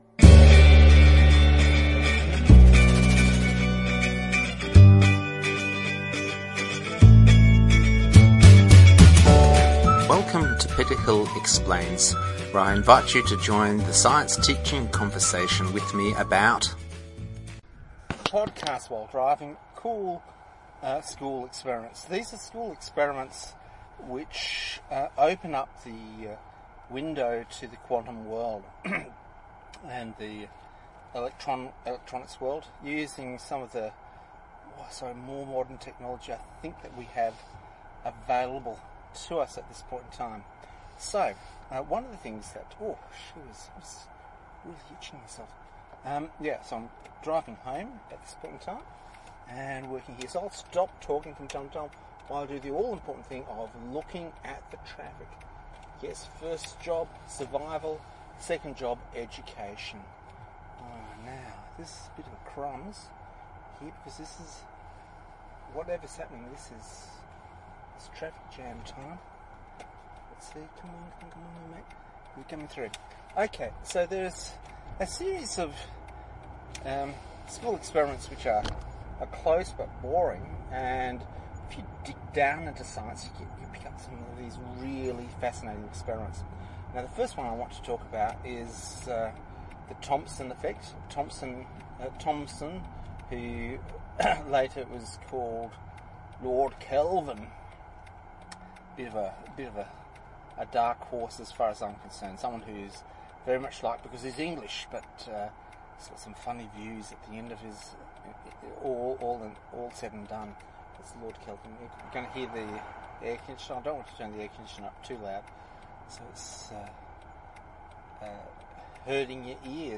There are others I talk through while driving home. So please forgive the intrusion of the the traffic indicators and of the traffic itself.